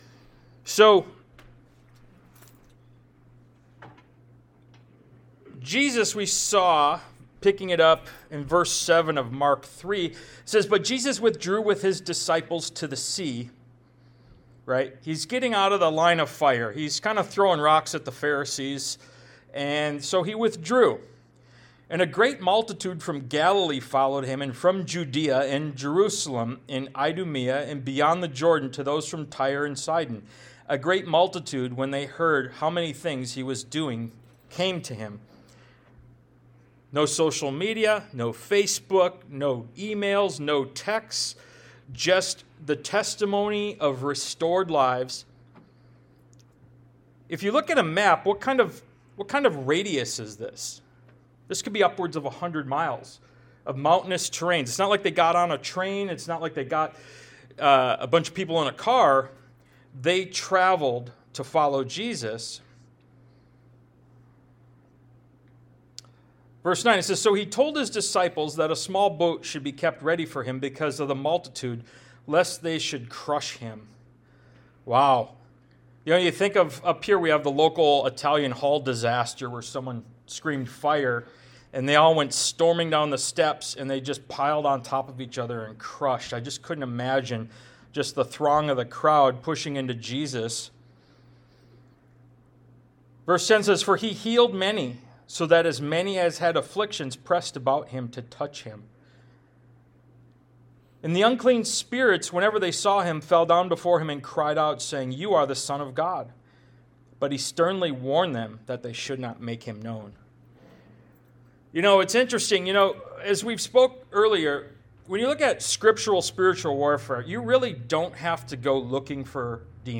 Ministry of Jesus Service Type: Sunday Morning « “Where Are You Withered?”